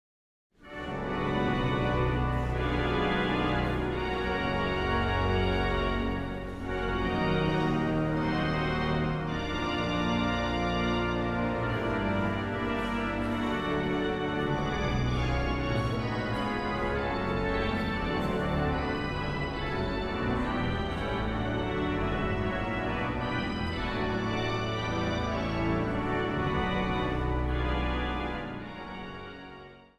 Zang | Mannenkoor
Zang | Samenzang